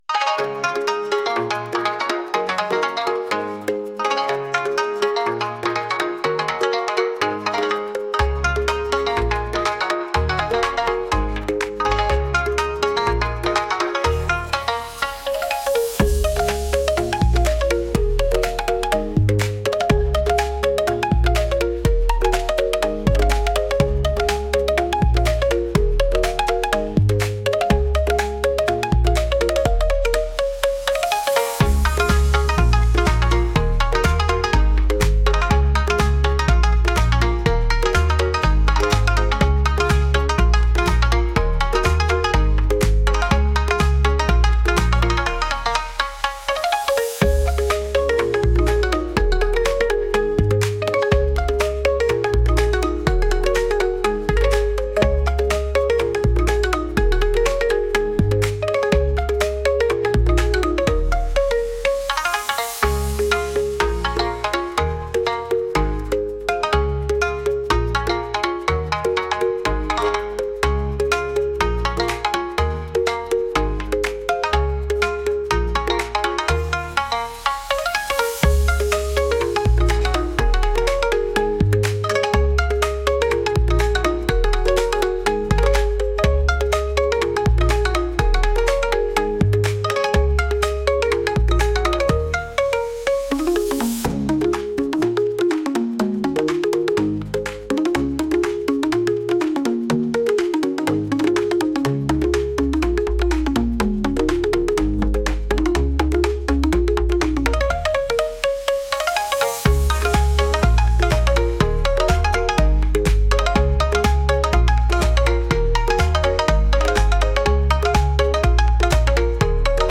お祭りのような軽快な音楽です。